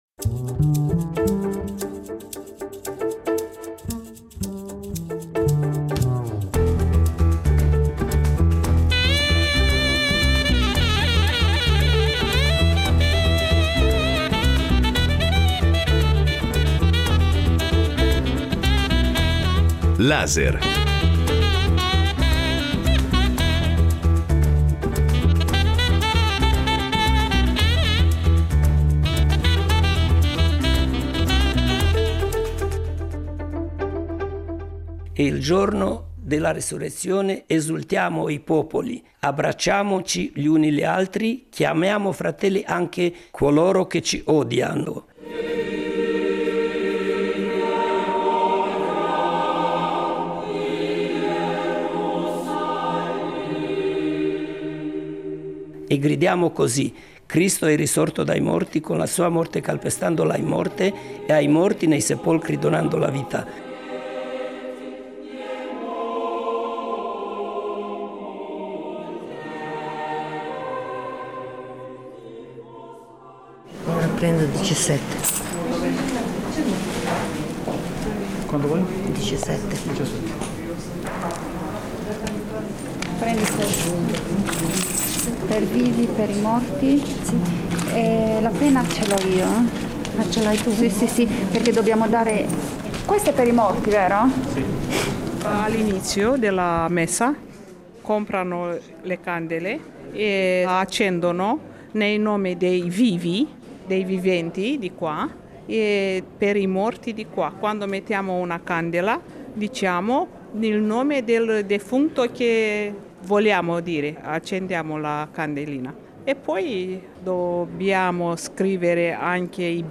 Per la prima volta la Chiesa ortodossa è lacerata da una guerra, i patriarchi di Mosca e Costantinopoli sono – non solo metaforicamente – su fronti diversi, e la religione ha invaso gli spazi della politica, della diplomazia e del conflitto armato. La Pasqua non è mai stata così tragica e difficile per il mondo religioso ortodosso, e “Laser” ha partecipato alle celebrazioni pasquali che si sono svolte in Ticino e nella vicina Como, per comprendere i sentimenti e la partecipazione dei fedeli in un momento storico segnato dal conflitto in Ucraina.